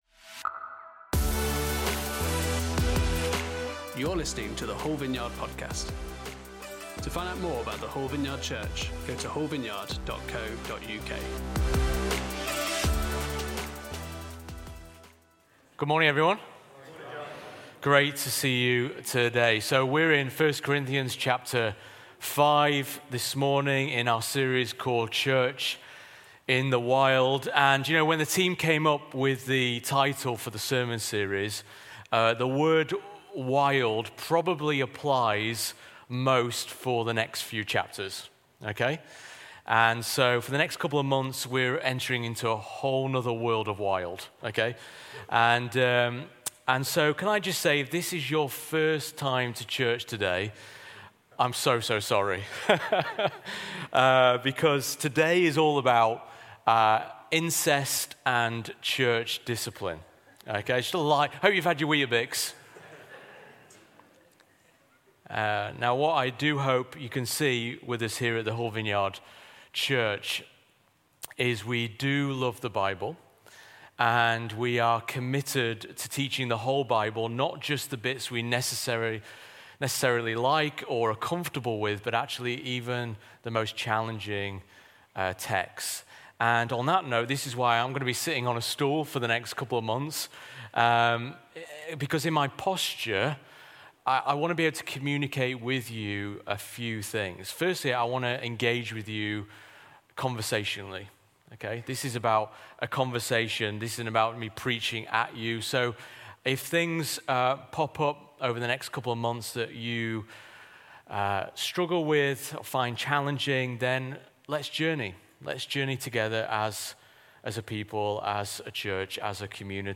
Series: Church In The Wild Service Type: Sunday Service